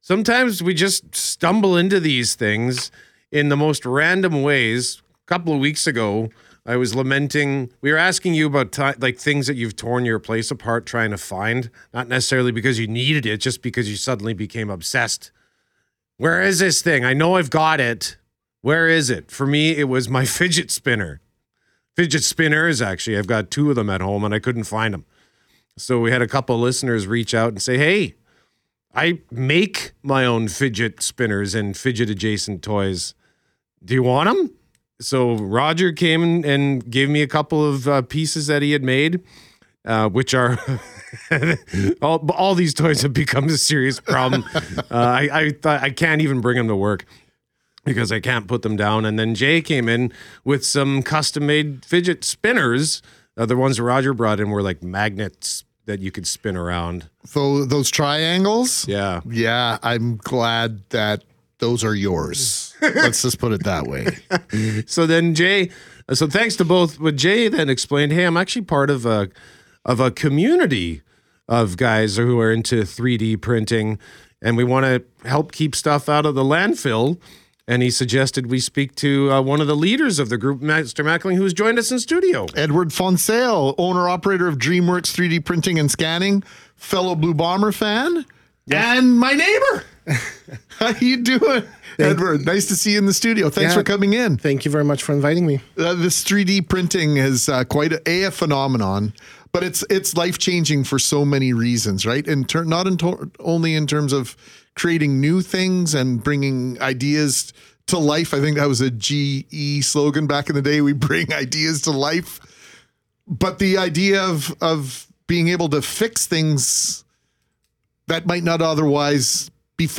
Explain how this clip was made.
EIB3D gets air on local radio station CJOB - Everything is Broken 3D